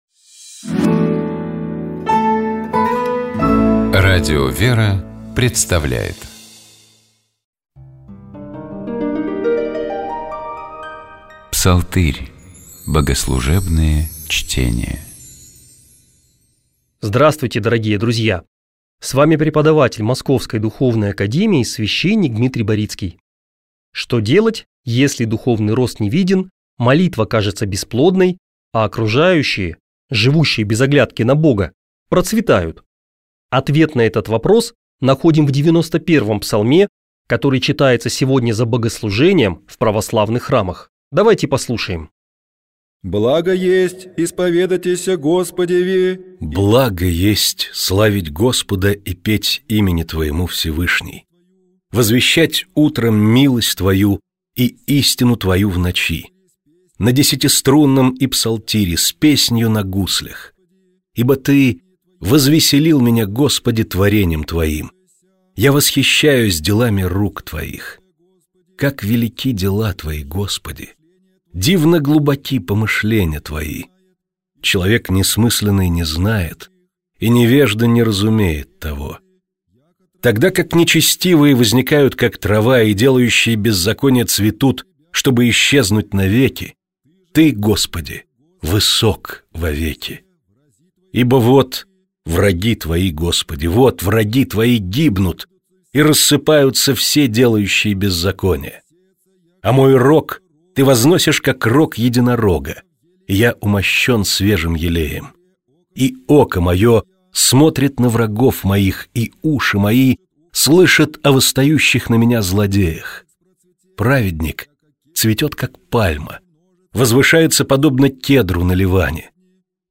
Богослужебные чтения